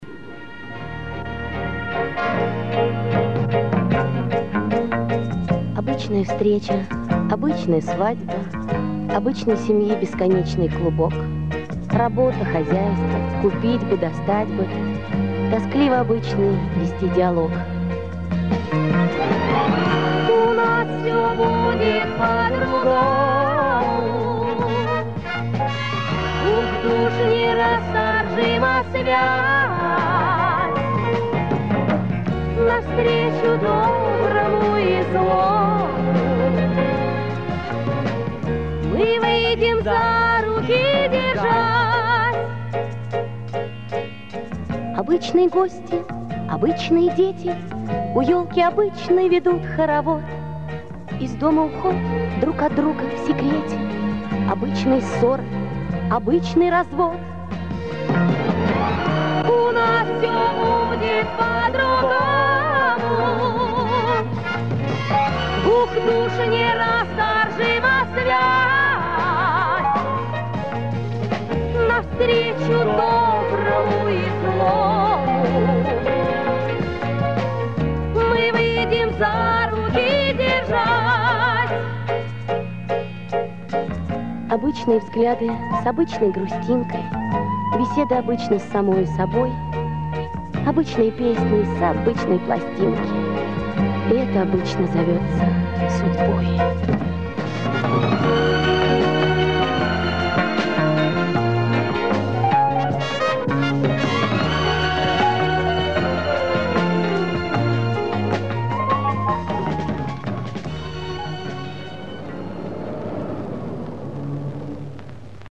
Режим: Mono